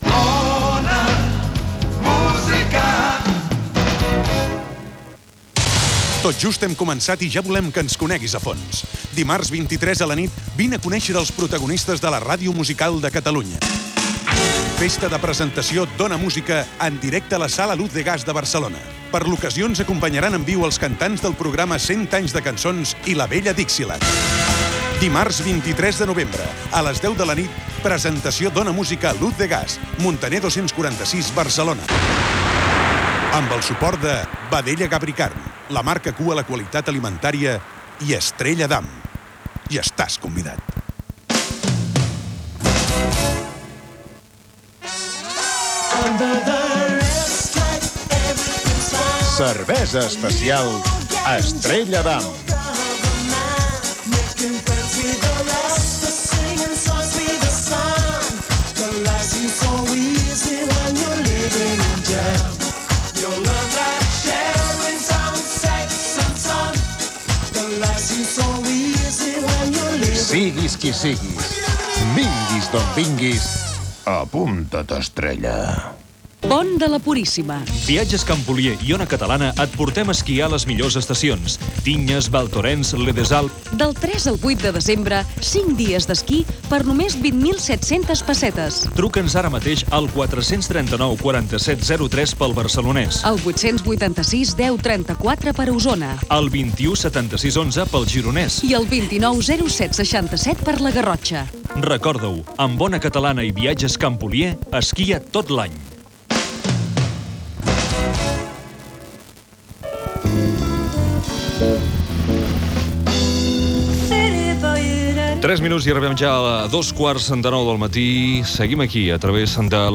Indicatiu, anunci de la festa de presentació d'Ona Música, publicitat i presentació d'un tema musical.
Musical
Primer dia d'emissió